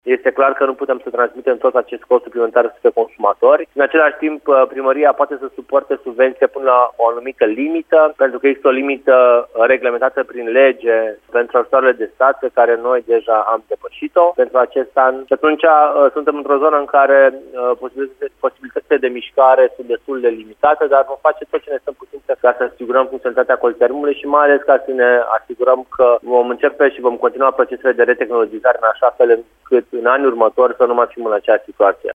Viceprimarul a declarat, la Radio Timișoara, că banii primiți, recent, de la guvern asigură funcționarea societății de termoficare, iar, anul viitor, municipalitatea va aloca bani din bugetul local.
Lațcău a ținut să precizeze, însă, că acest cost nu va fi suportat integral de abonați: